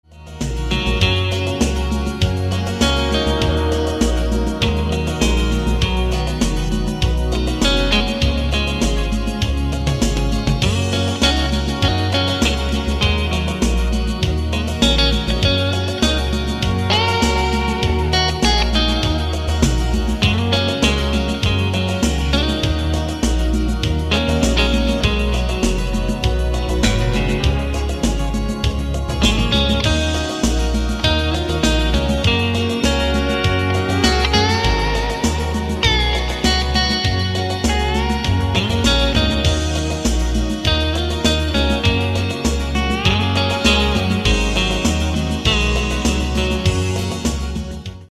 • Качество: 256, Stereo
красивые
спокойные
инструментальные
электрогитара
фолк-рок